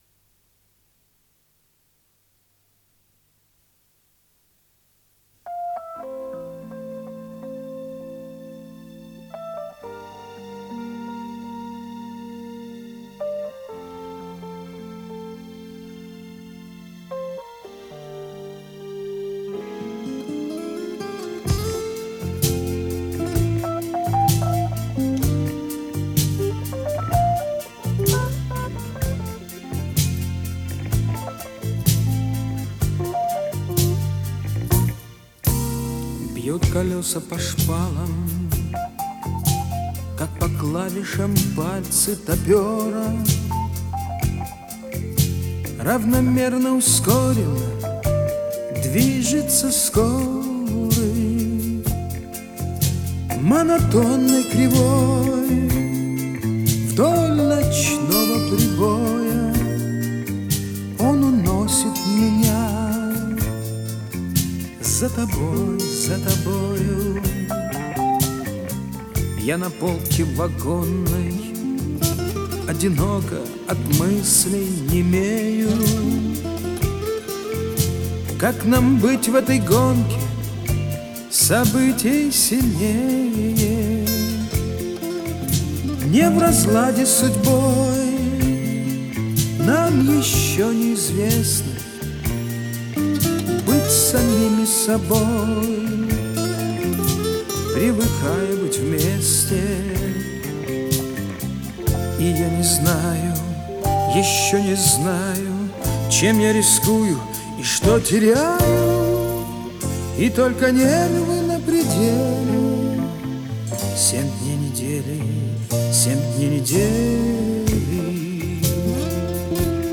Жанр: Pop Music
Устройство воспроизведения: Radiotehnika АРИЯ 102 СТЕРЕО
Обработка: Обработка не производилась.